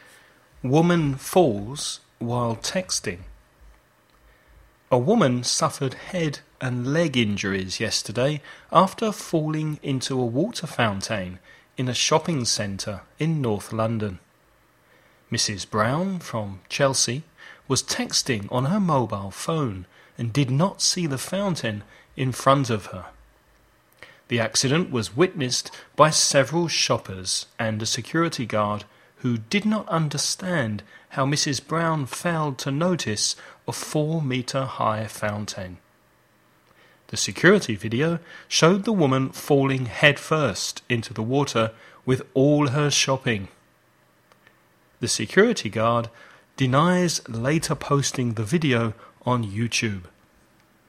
Dictados en Inglés - English dictations
dictation1.mp3